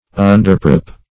Search Result for " underprop" : The Collaborative International Dictionary of English v.0.48: Underprop \Un`der*prop"\, v. t. To prop from beneath; to put a prop under; to support; to uphold.